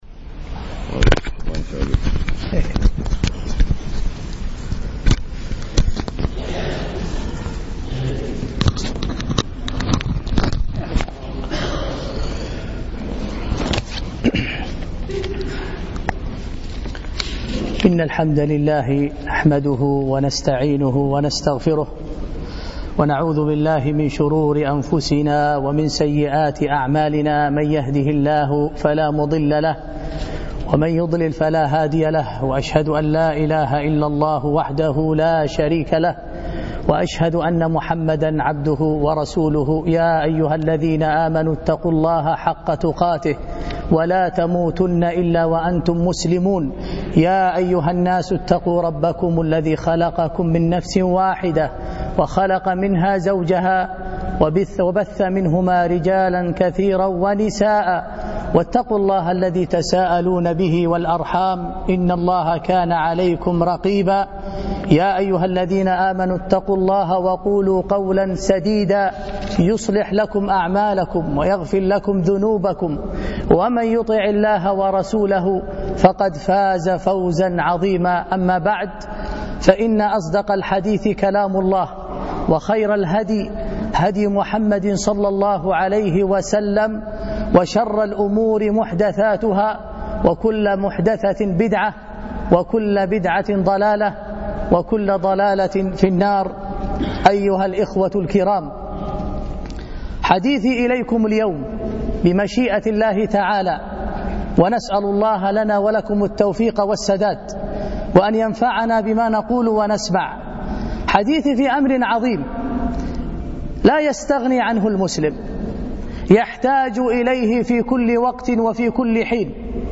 الاثنين 13 رمضان 1434 بمسجد سعد بن عبادة منطقة خيطان